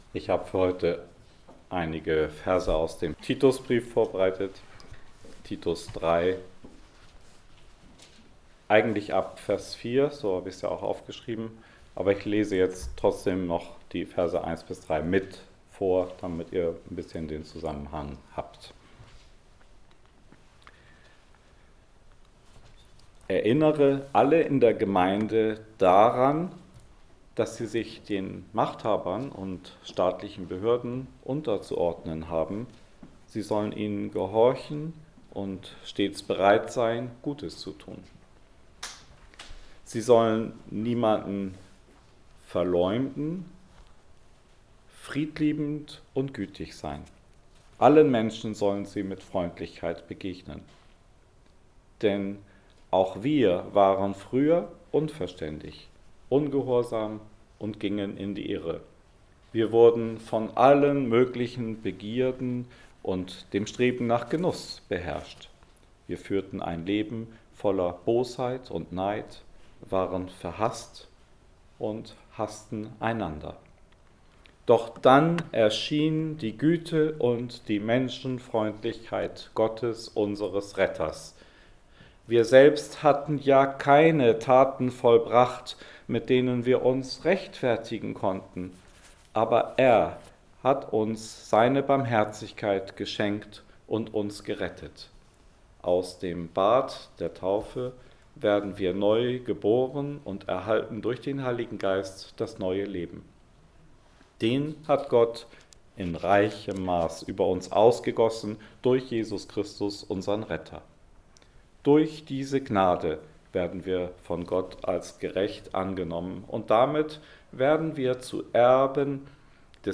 Weihnachtsgottesdienst - Leben aus Hoffnung